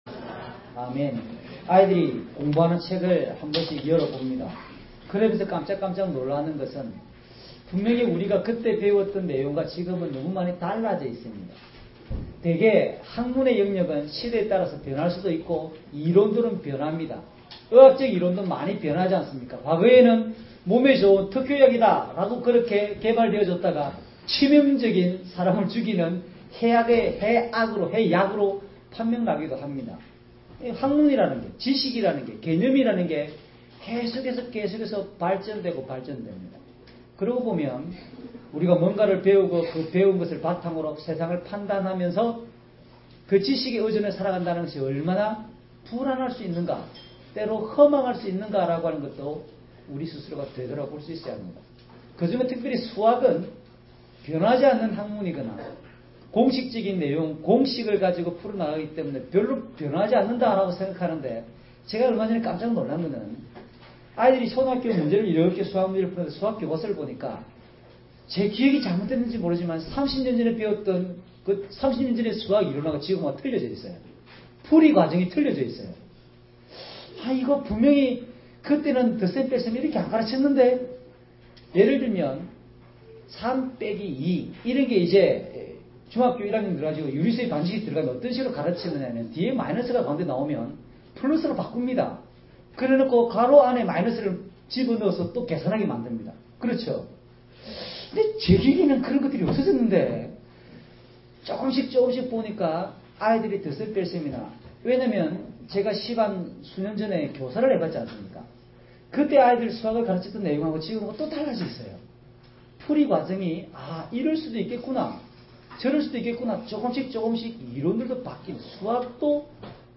주일설교 - 09년 06월 07일 "진리안에서 자유를 누립시다" (눅12:1-12)